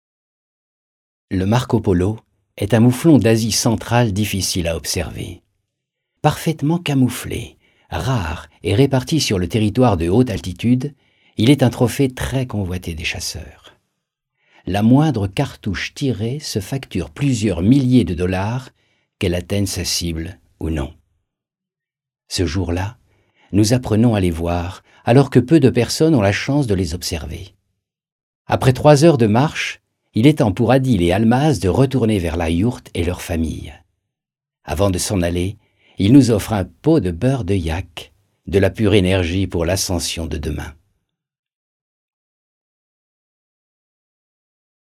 1er extrait: voix off documentaire